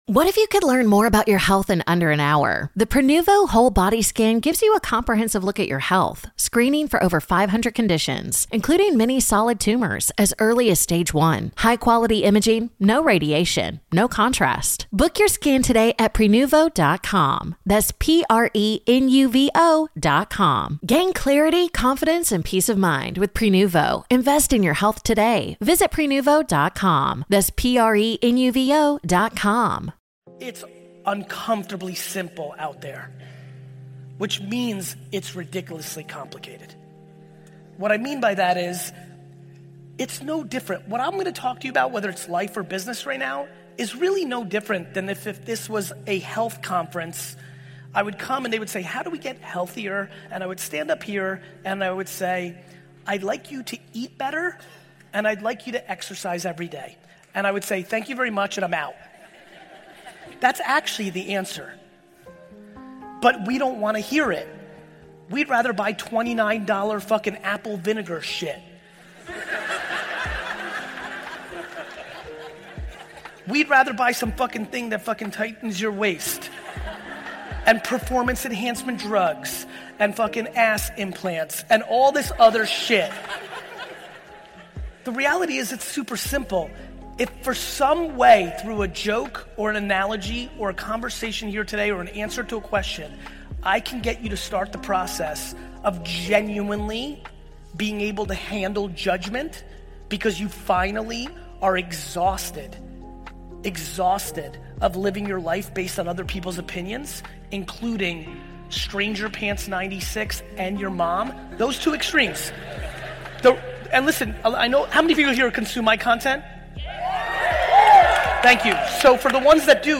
Gary Vee - Stop being a people pleaser motivational speech
In this high-energy episode, Gary Vaynerchuk delivers a no-BS wake-up call: stop being a people pleaser if you ever want to build something real, live on your own terms, and stop feeling drained every single day. With his trademark intensity and street-smart wisdom, Gary breaks down why constantly saying yes to everyone else is quietly killing your dreams, your time, your energy, and your self-respect.